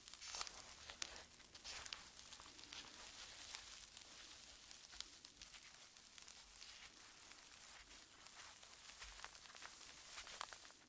rustling_long_2.ogg